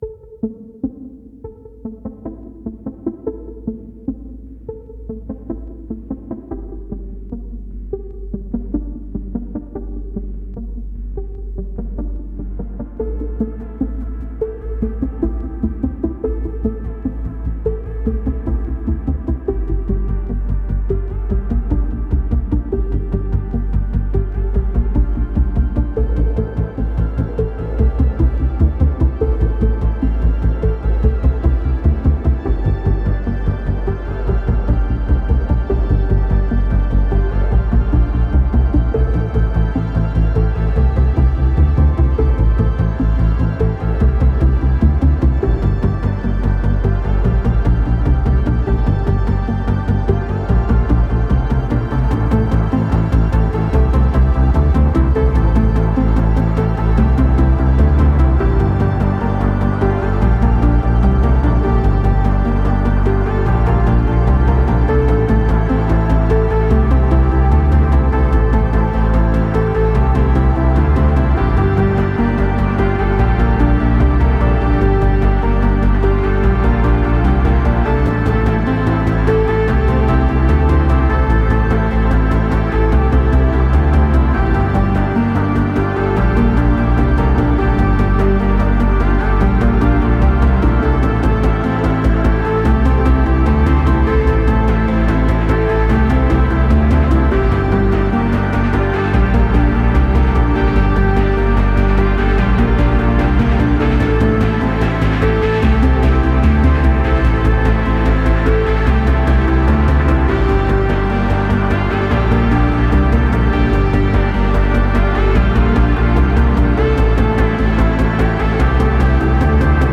Pulsating synth arpeggios and hazy textures.